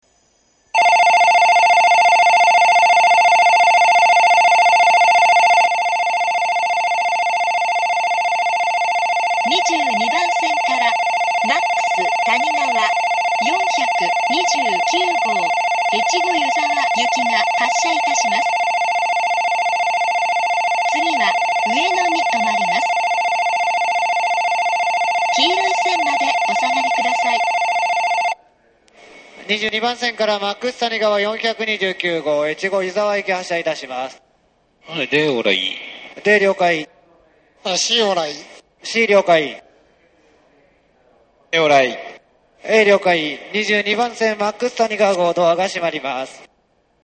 発車時には全ホームROMベルが流れます。
遅くとも東北新幹線全線開業時には、発車ベルに低音ノイズが被るようになっています。ただし、新幹線の音がうるさいので密着収録していてもほとんどわかりません。
２２番線発車ベル Ｍａｘたにがわ４２９号越後湯沢行きの放送です。